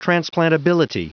Prononciation du mot transplantability en anglais (fichier audio)
Prononciation du mot : transplantability